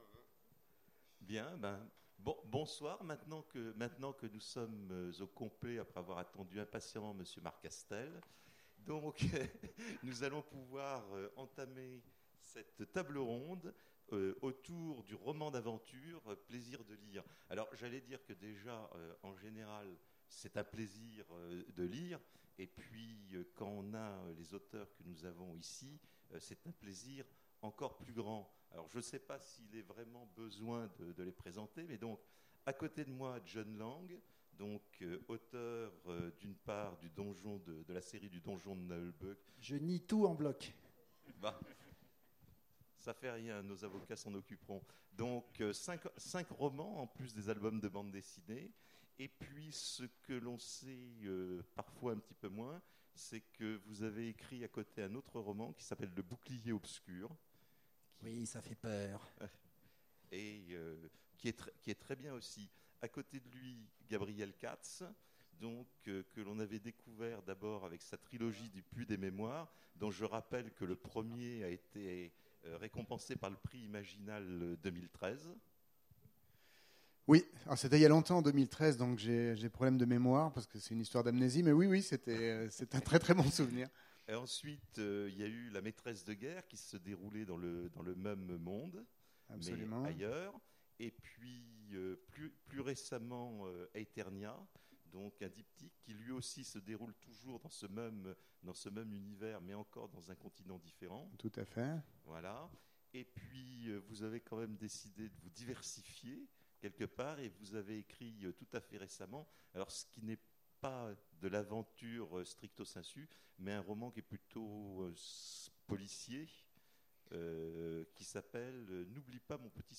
Imaginales 2016 : Conférence Romans d’aventure…